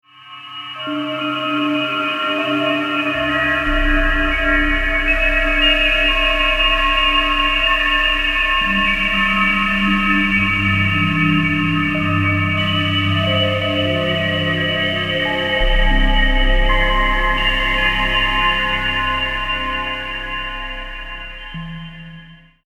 The local currency is represented by sounds. Values are digitally converted into audio signals.